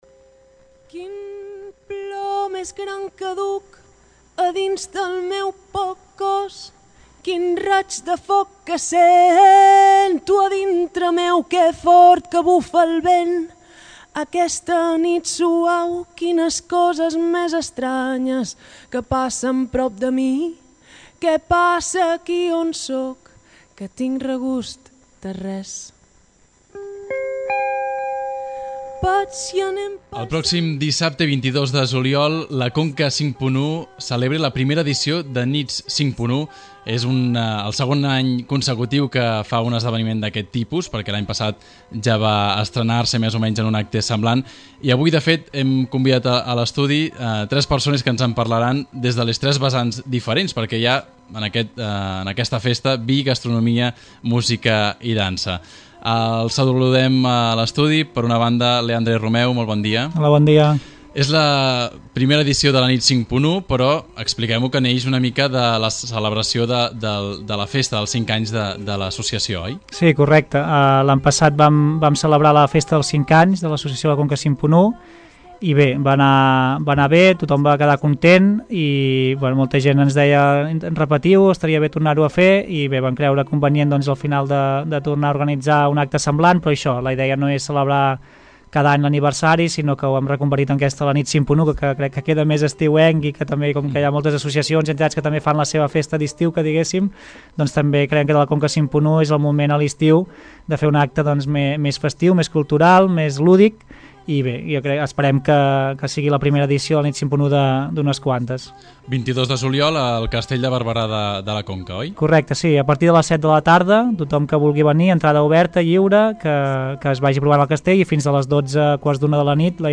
Entrevista-Conca-51-Nit-51.mp3